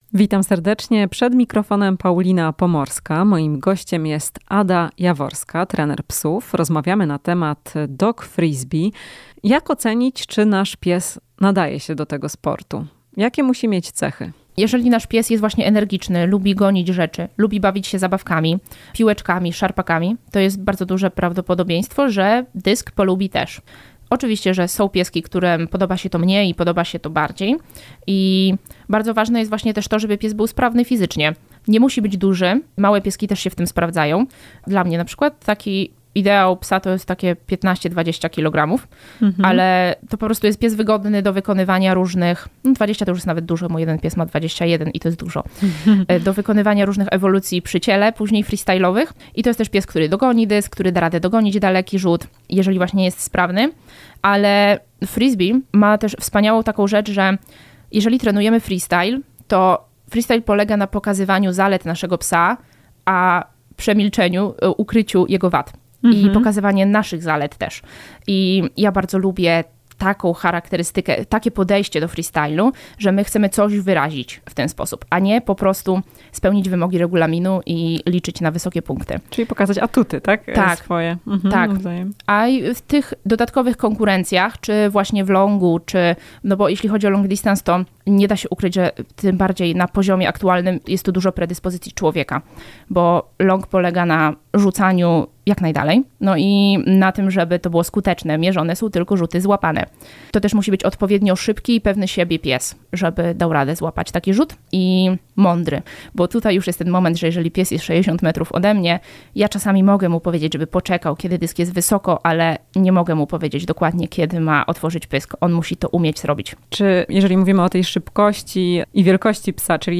Jak rozpoznać czy nasz pies nadaje się do dogfrisbee? O tym w rozmowie